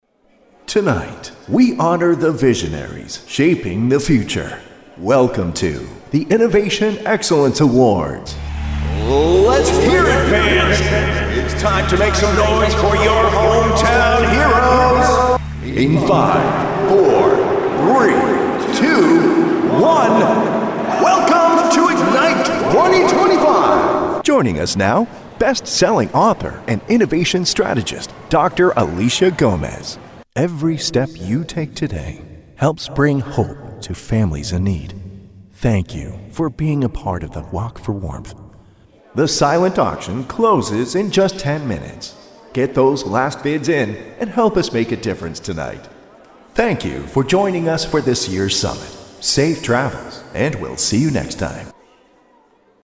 Live Announce